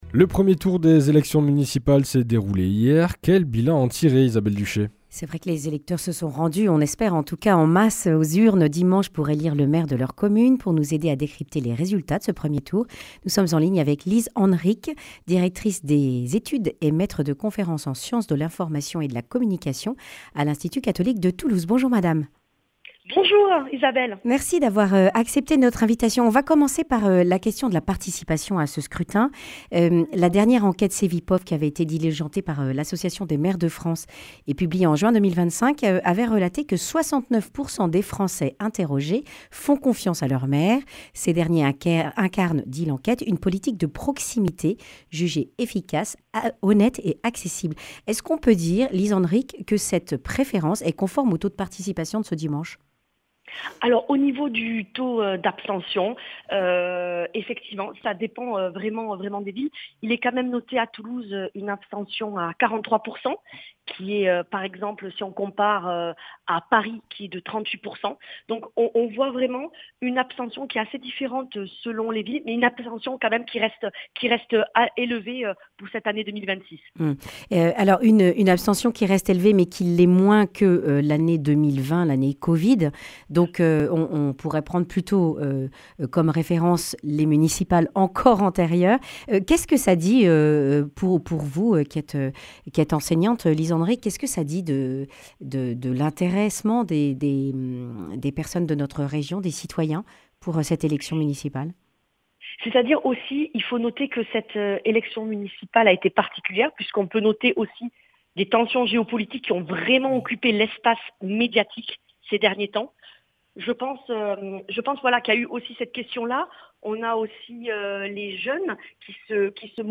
lundi 16 mars 2026 Le grand entretien Durée 10 min